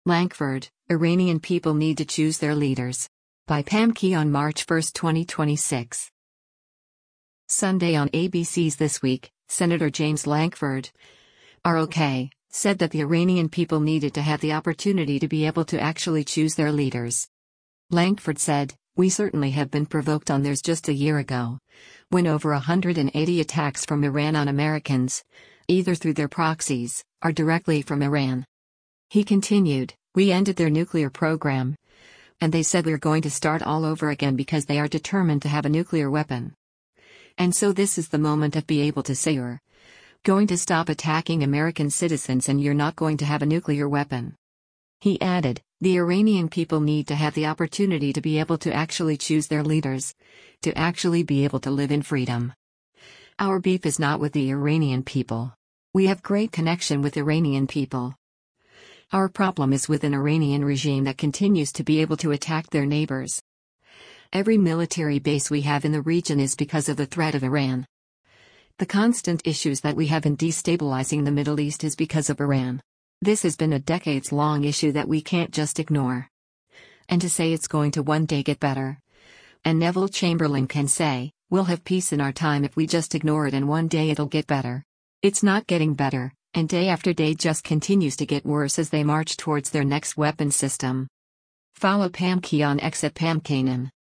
Sunday on ABC’s “This Week,” Sen. James Lankford (R-OK) said that the Iranian people needed “to have the opportunity to be able to actually choose their leaders.”